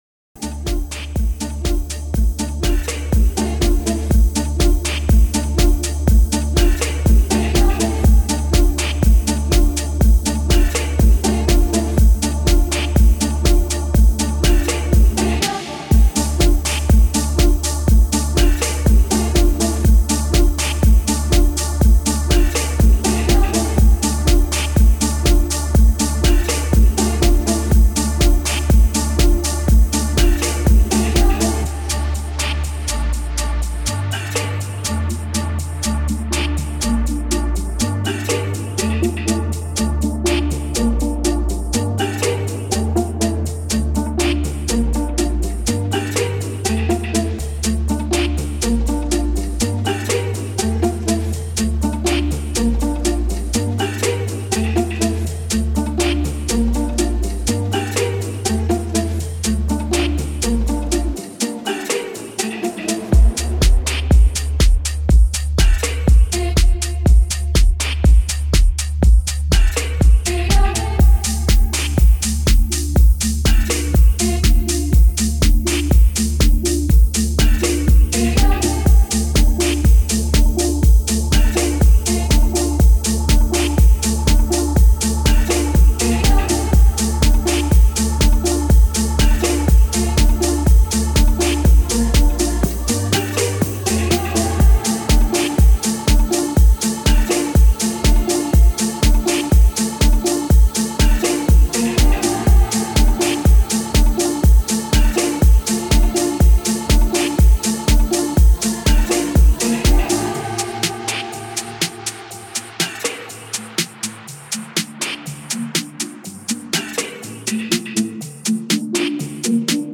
Deep House